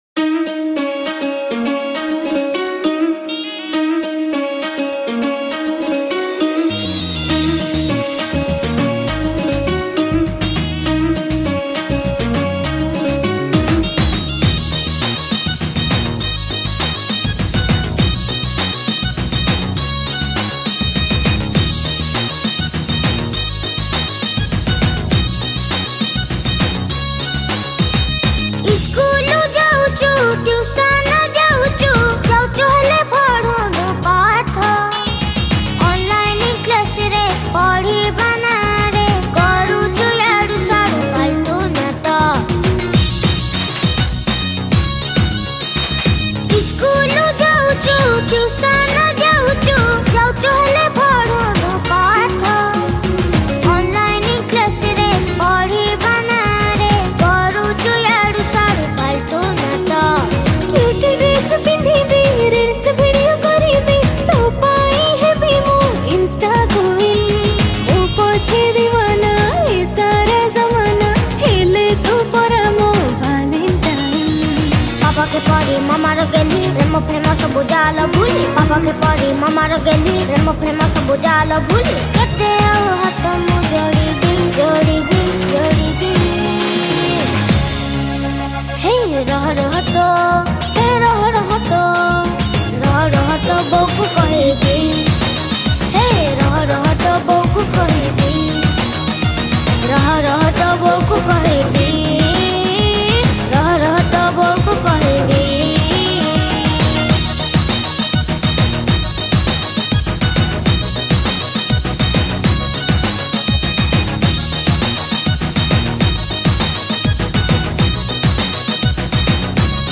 Single Odia Album Song 2022